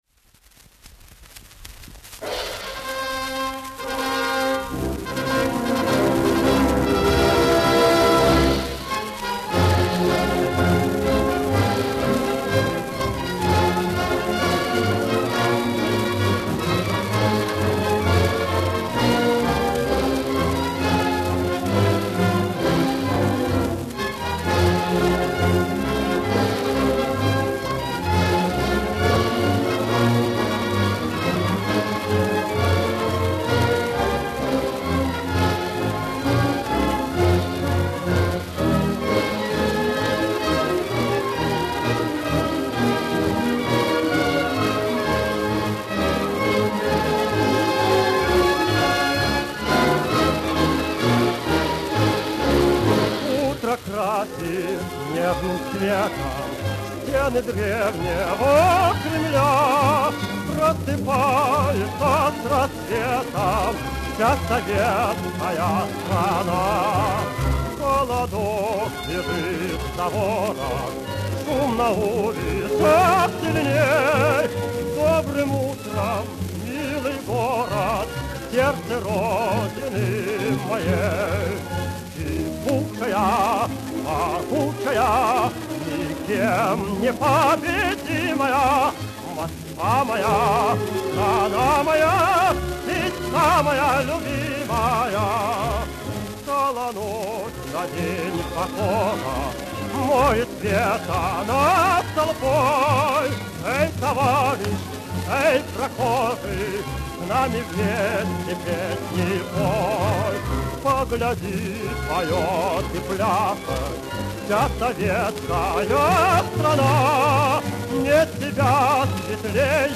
Солист Государственной филармонии